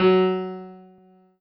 piano-ff-34.wav